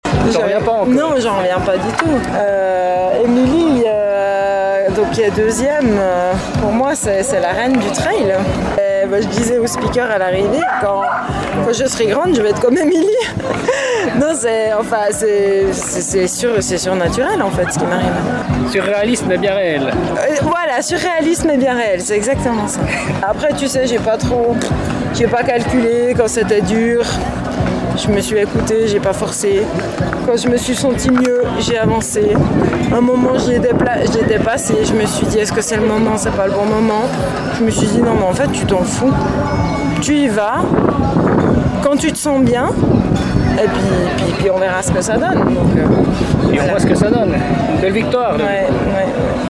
Découvrez sa géniale réaction à l’arrivée :